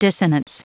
Prononciation du mot dissonance en anglais (fichier audio)
Prononciation du mot : dissonance